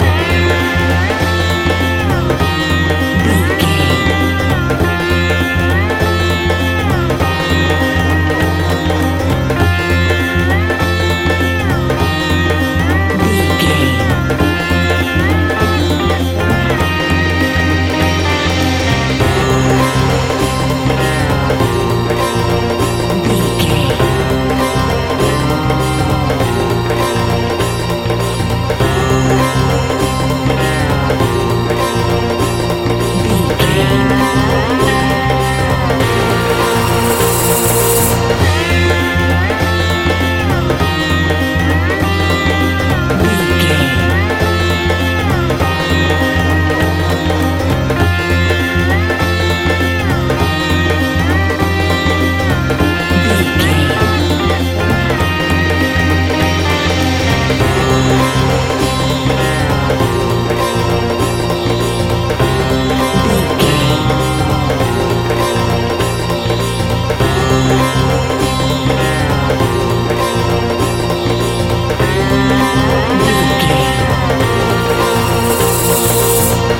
Ionian/Major
mystical
hypnotic
tabla